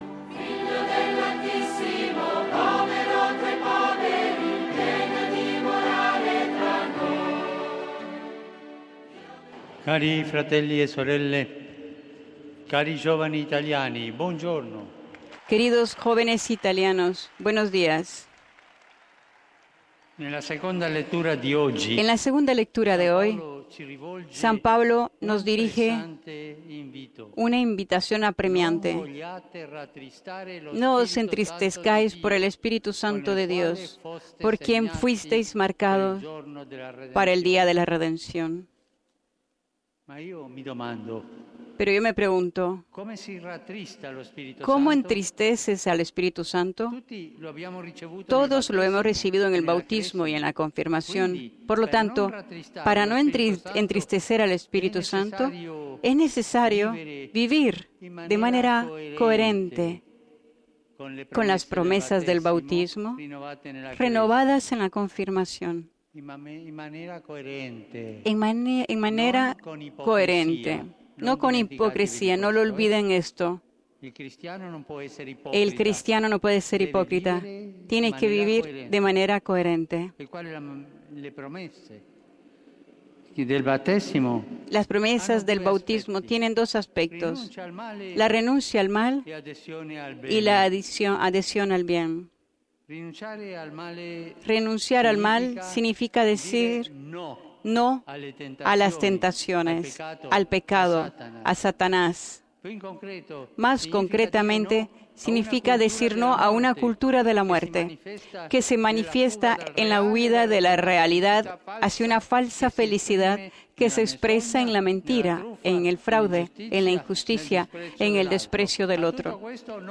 “¡Hoy los exhorto a ser protagonistas del bien! No se sientan contentos cuando no hagan el mal; cada uno es culpable del bien que podía hacer y no lo ha hecho”, lo dijo el Papa Francisco en su alocución antes de rezar la oración mariana del Ángelus del XIX Domingo del Tiempo Ordinario, Domingo en el cual el Obispo de Roma encontró a los jóvenes peregrinos de las Diócesis de Italia.
Después de rezar a la Madre de Dios, el Papa Francisco saludo a los fieles y peregrinos congregados en la Plaza de San Pedro.